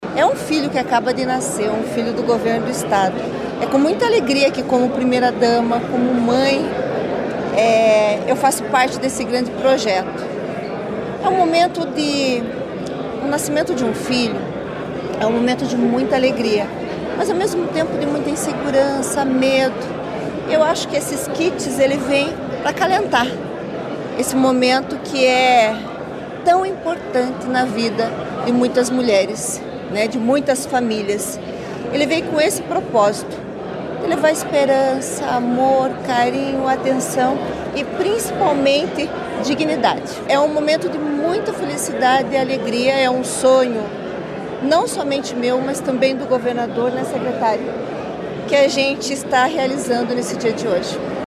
Sonora da primeira-dama do Paraná, Luciana Saito Massa, sobre o programa Nascer Bem Paraná